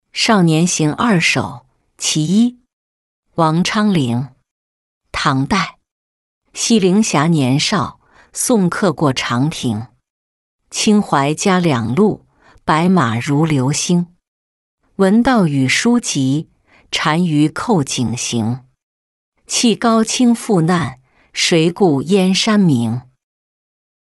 少年行二首·其一-音频朗读